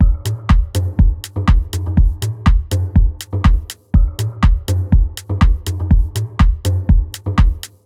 • house - techno beat passage 122bpm - Fm - 122.wav
A loop that can help you boost your production workflow, nicely arranged electronic percussion, ready to utilize and royalty free.